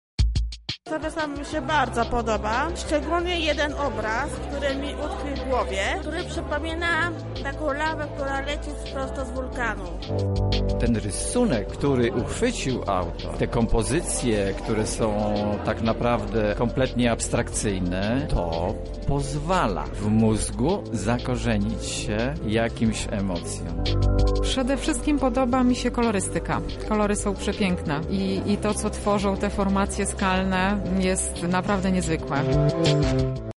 Widzowie żywo opowiadali o swoich wrażeniach.